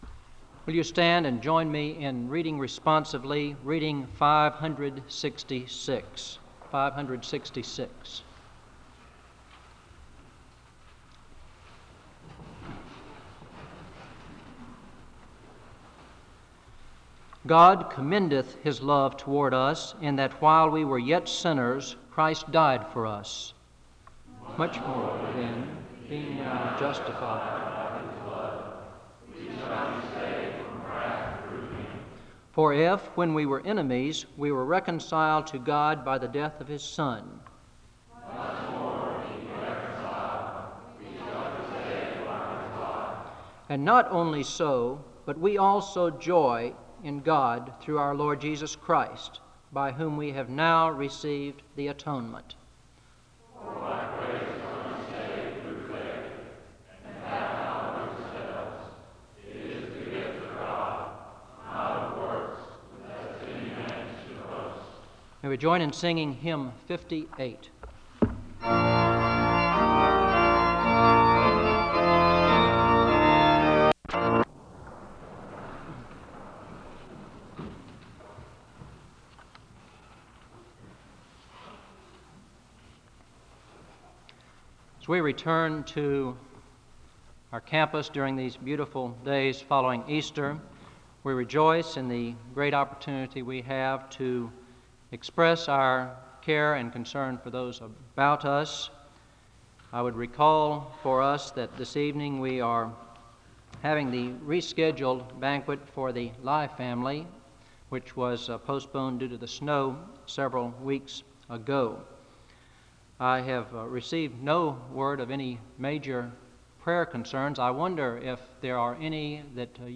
The service begins with a responsive reading (00:00-01:18). The speaker gives community announcements and leads in a time of prayer (01:19-04:02).
The choir sing a song of worship (05:47-09:15).
SEBTS Chapel and Special Event Recordings SEBTS Chapel and Special Event Recordings